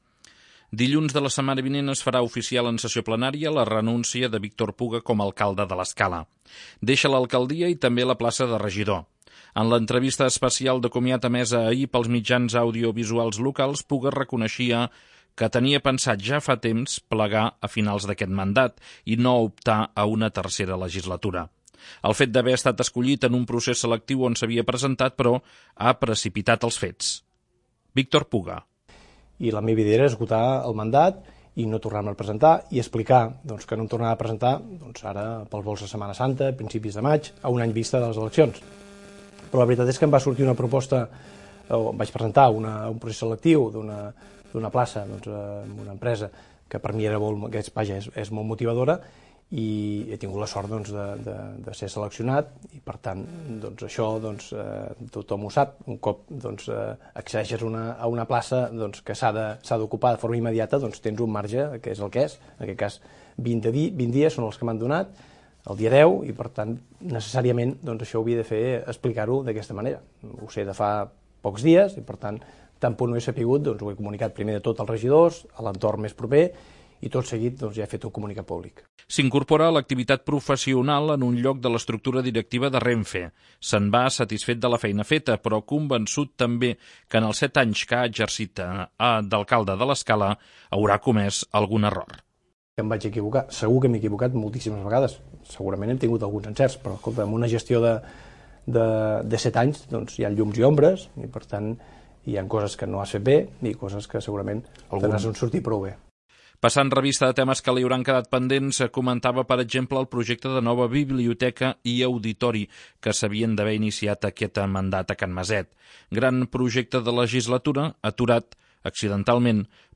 En l'entrevista especial de comiat emesa ahir pels mitjans audiovisuals locals, Puga reconeixia que tenia pensat fa temps plegar a final de mandat i no optar a una tercera legislatura.